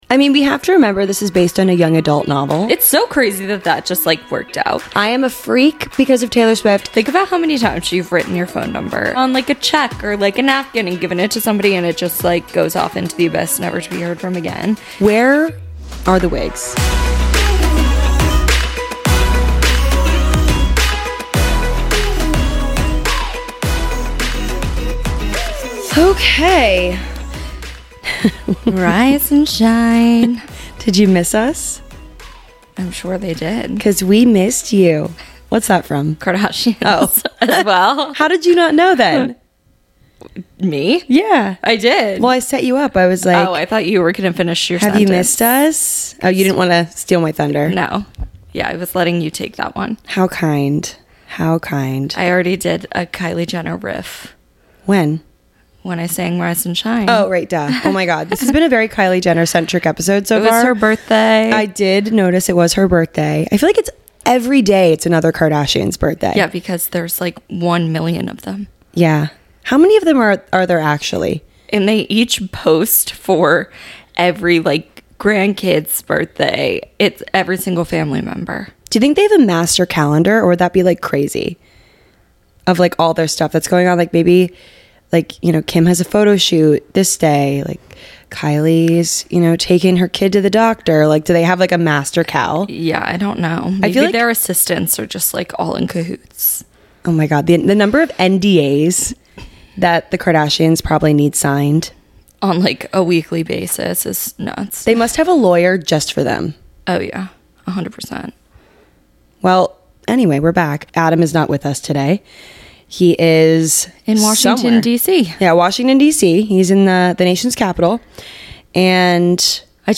We're just two ordinary girls!